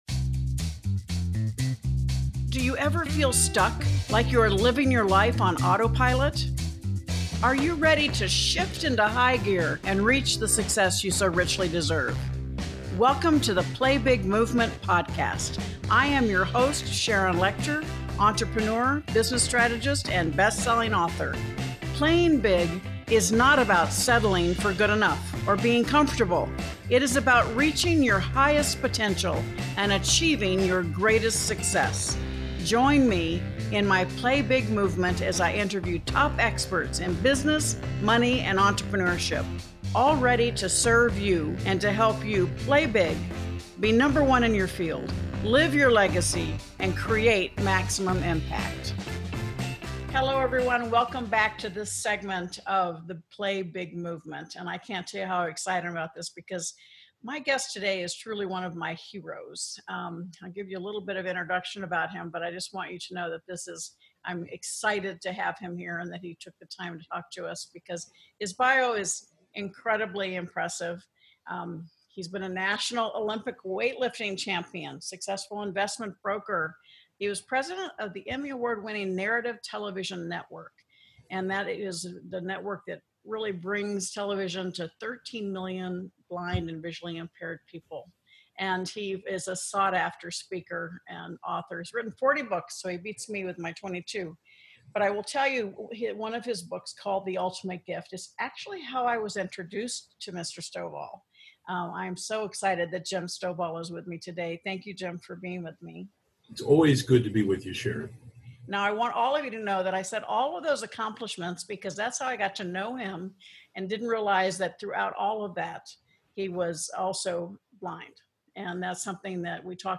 Today's guest is truly one of my heroes.